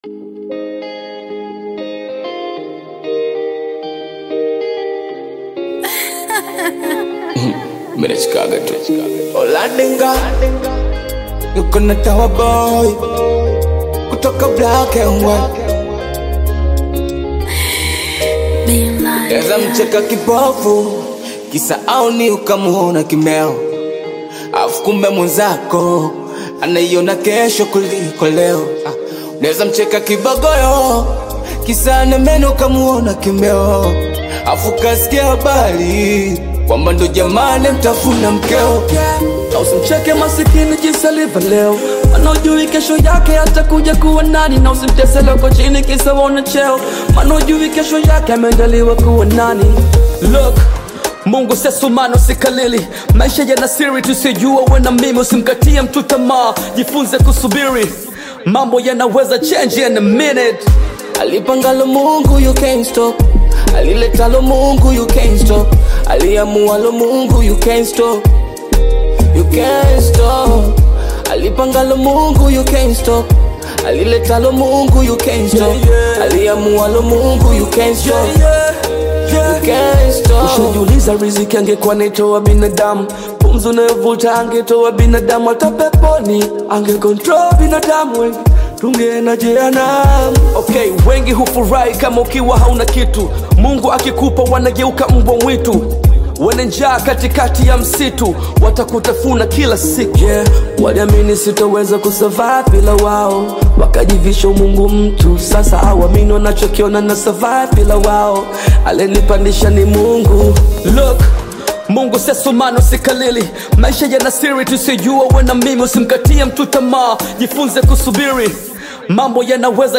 the dynamic rap artist
signature energetic rap flow